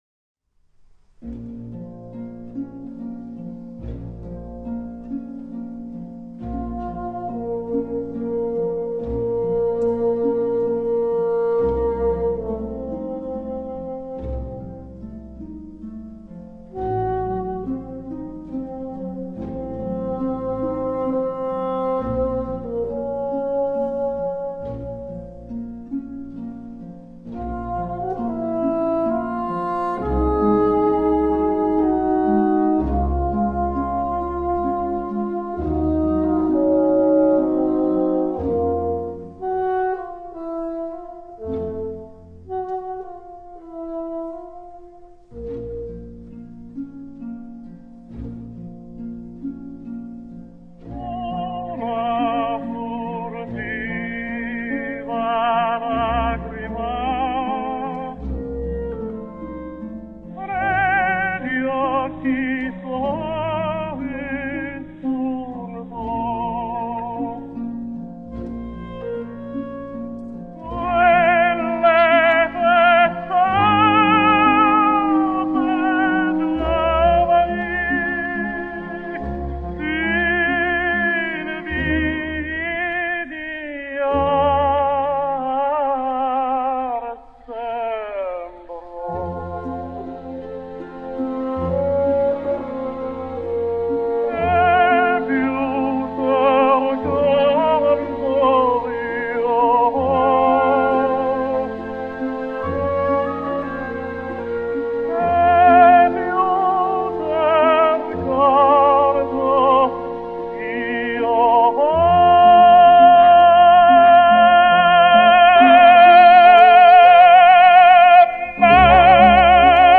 Remastered